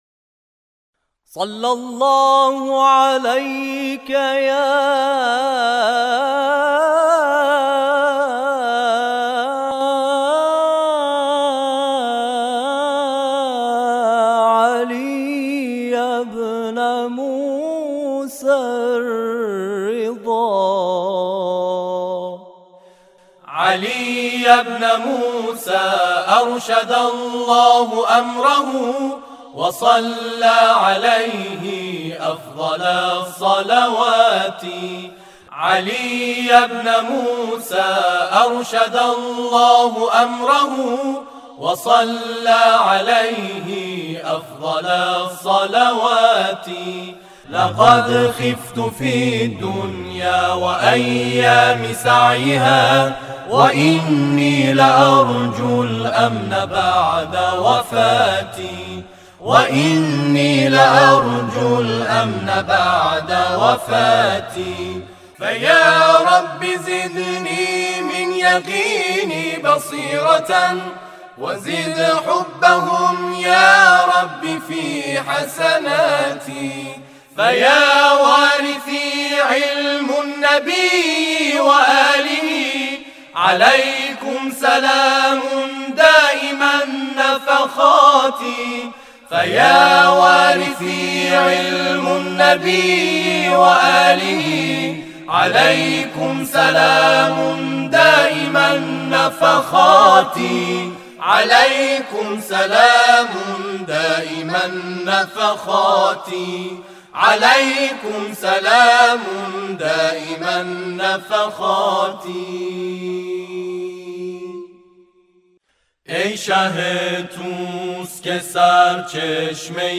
گروه تواشیح و همخوانی
برای اولین بار در حرم مطهر امام رضا(ع) اجرا کرد.